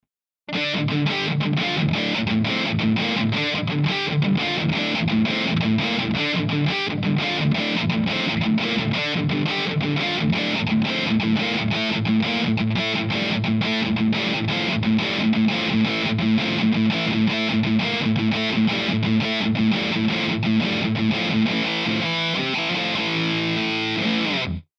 Пример звука Peavey Classic на Boss MT-2
Записан недорогим микрофоном Philips MD 600.                  Без обработки.
Записано на гитаре Fender Squier